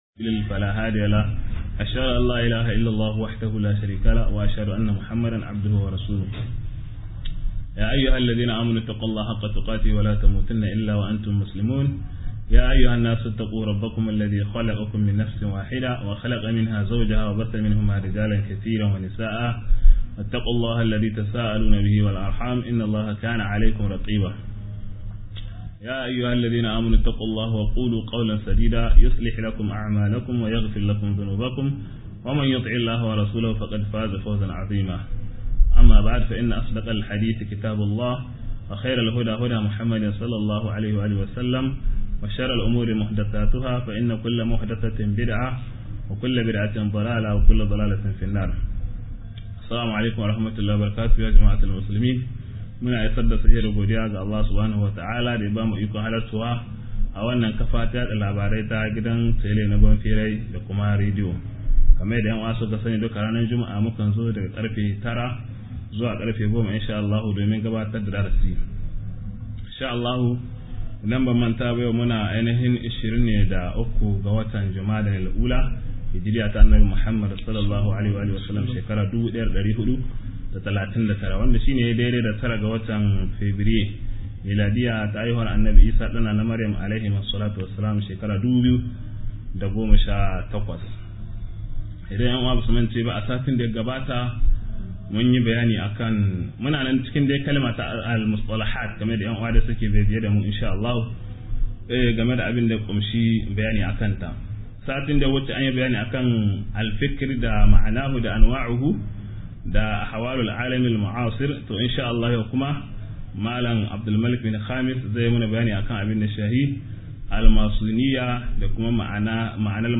06-bayray front maçon~1 - MUHADARA